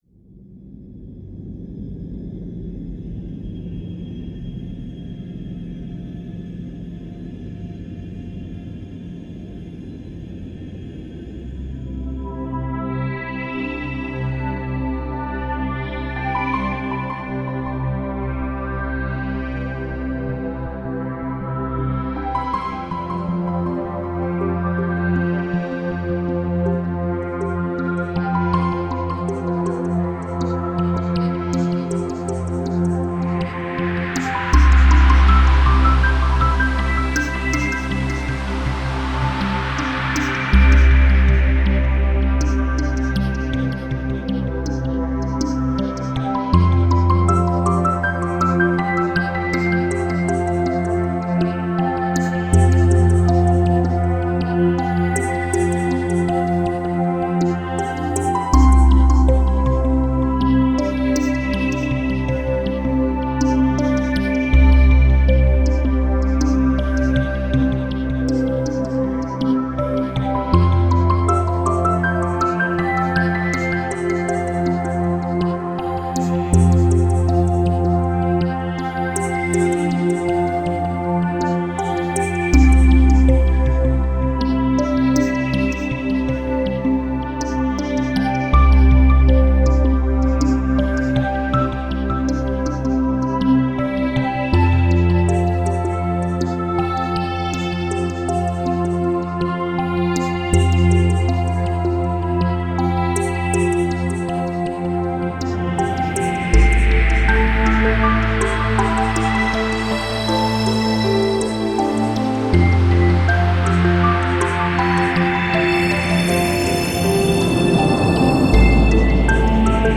Musica-espacial.-Galaxias.-Relaxing-music.-Musica-relajante..m4a